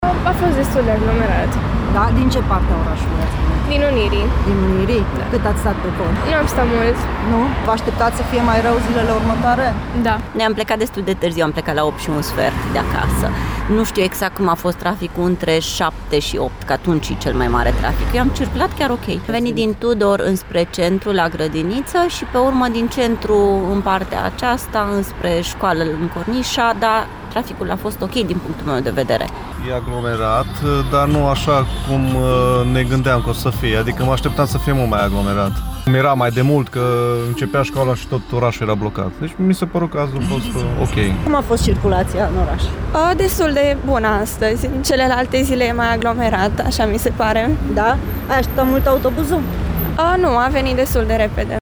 Participanţii la trafic spun că deşi a fost aglomerat s-a circulat destul de bine, dar se aşteaptă ca de mâine să fie mai intensă curculația: